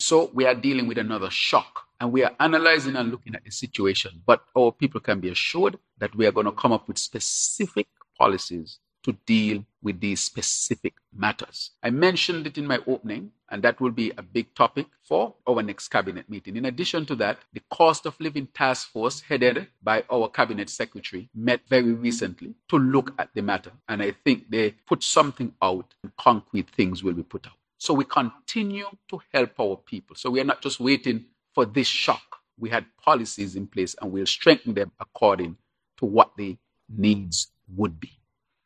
Speaking at a Cabinet-wide press conference on March 30th, 2026, Dr. Drew acknowledged that external market forces due the conflict in the Middle East are driving fuel price increases, with direct consequences for small island economies like Saint Kitts and Nevis.